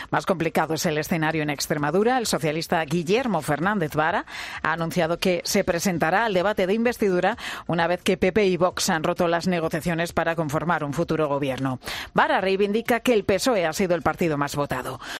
En la rueda de prensa posterior al Consejo de Gobierno, ha recriminado las palabras de la candidata popular que ha asegurado que "si que hay que ir a elecciones de nuevo, se va"